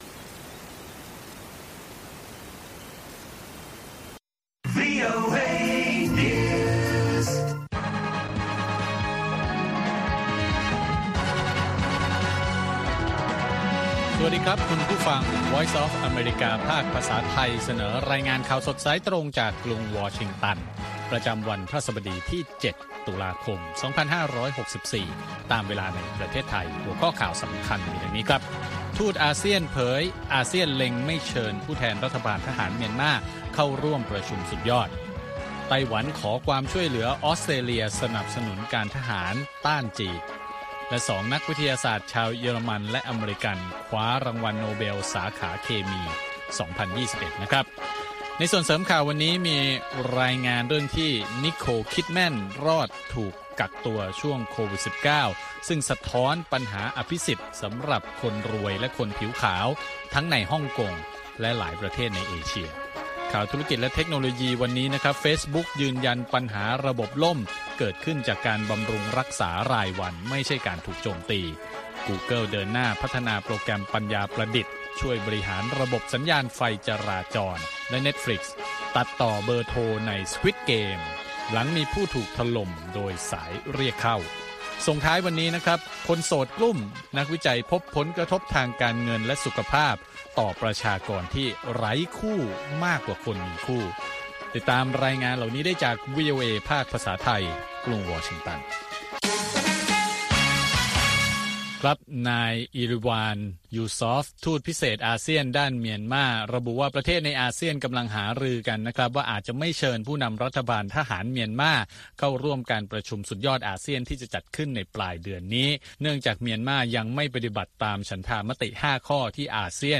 ข่าวสดสายตรงจากวีโอเอ ภาคภาษาไทย 6:30 – 7:00 น. ประจำวันพฤหัสบดีที่ 7 ตุลาคม 2564 ตามเวลาในประเทศไทย